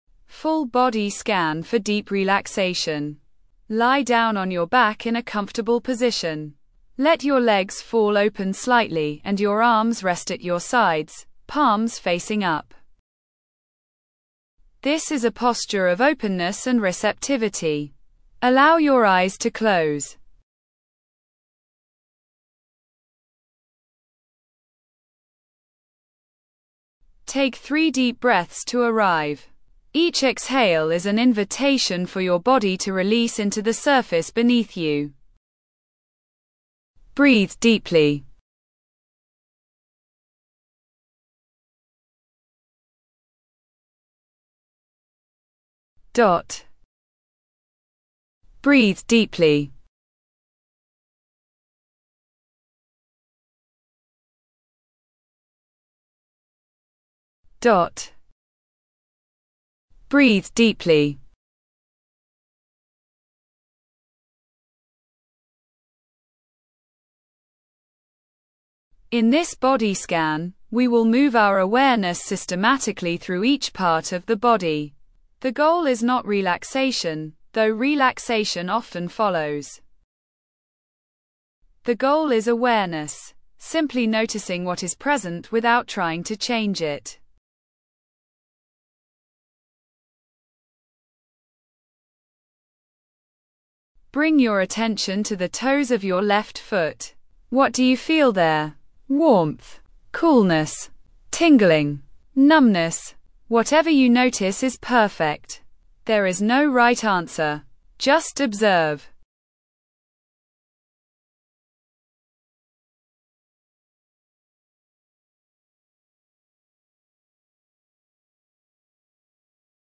body scan 20 min beginner
Full Body Scan Meditation for Deep Relaxation